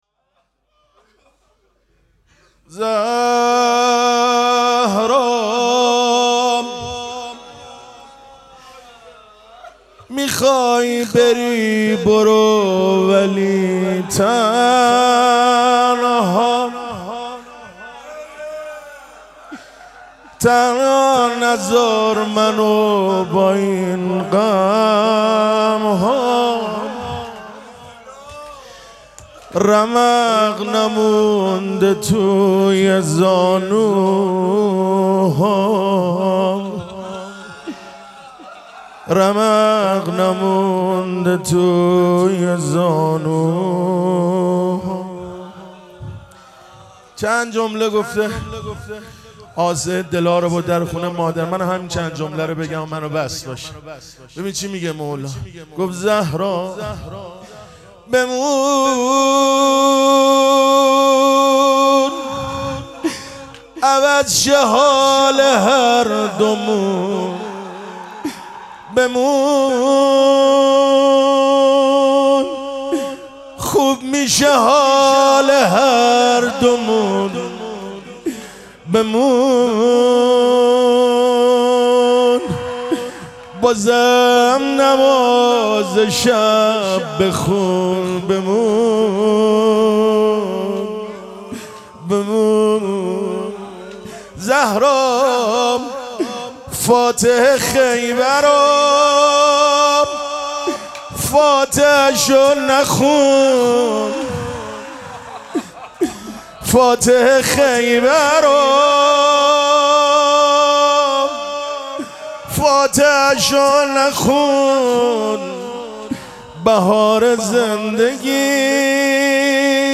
مراسم مناجات شب یازدهم ماه مبارک رمضان
روضه
مداح